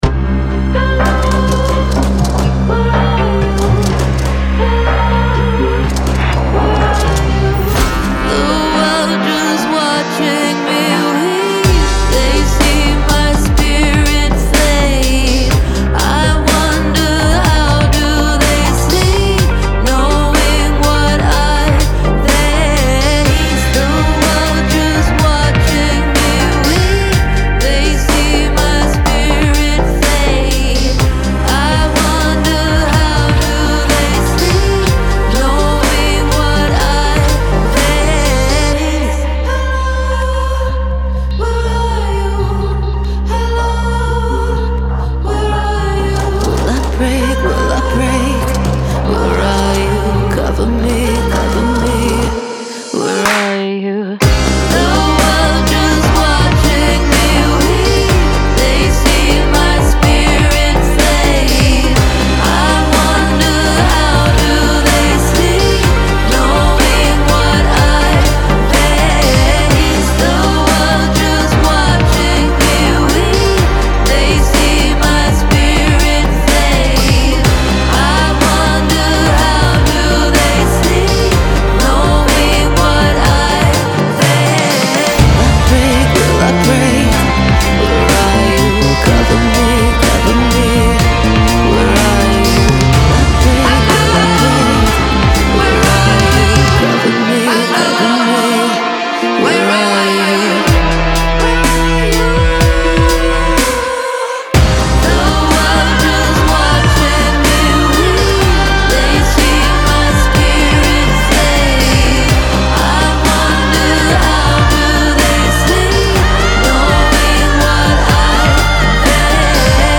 Vocal performance